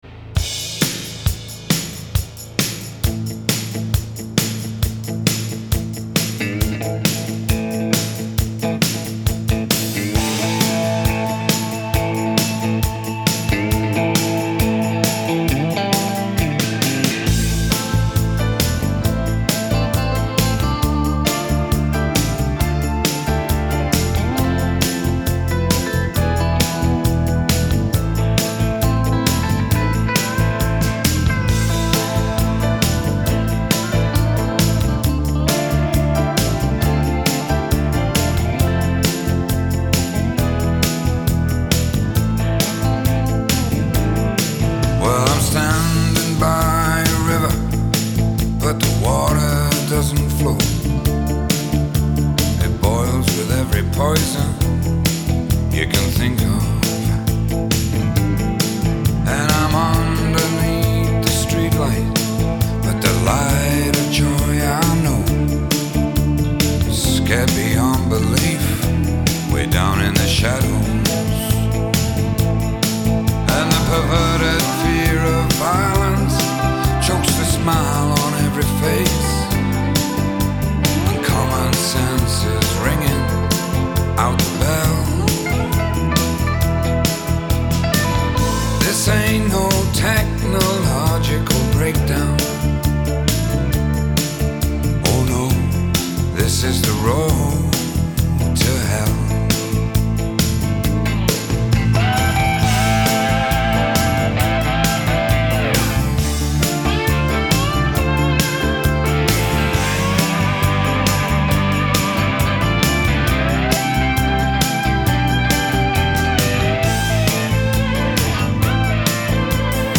Инструменты гитара, губная гармошка
Жанры блюз, поп, рок, софт-рок